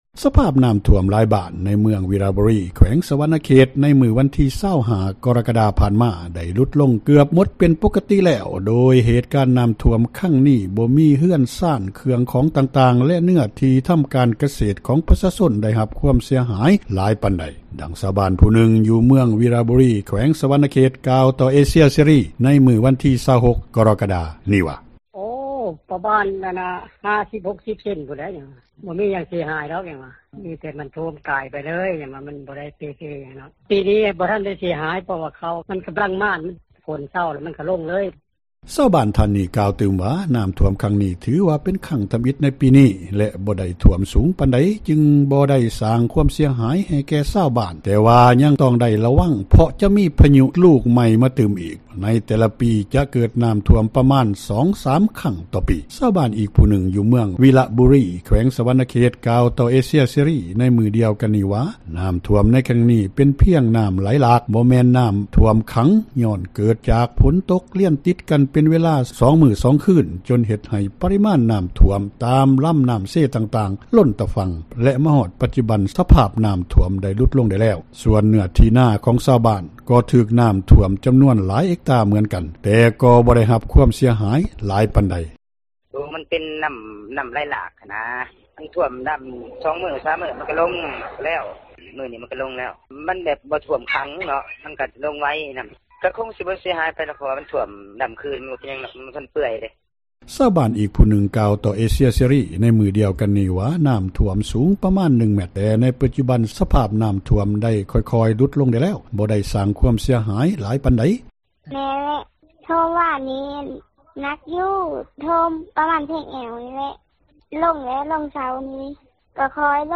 ດັ່ງຊາວບ້ານ ຜູ້ນຶ່ງຢູ່ເມືອງວິລະບູຣີ ກ່າວຕໍ່ວິທຍຸເອເຊັຽເສຣີ ໃນມື້ວັນທີ 26 ກໍຣະກະດາ ນີ້ວ່າ: